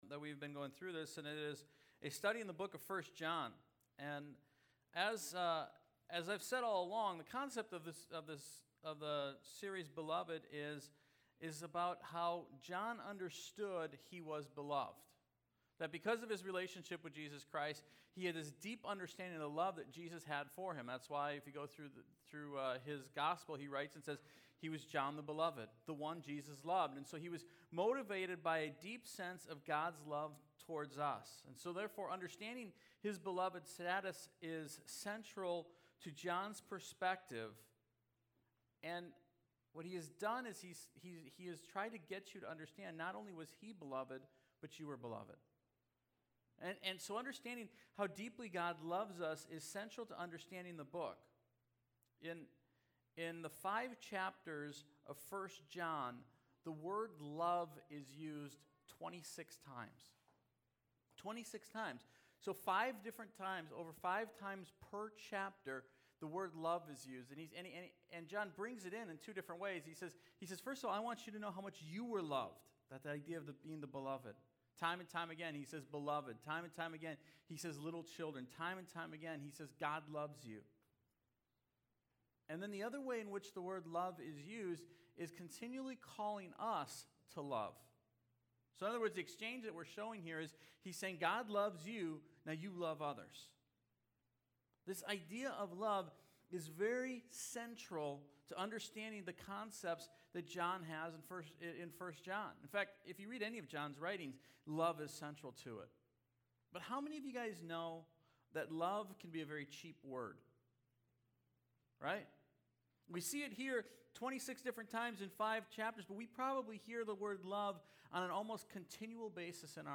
Bay View Sermon Media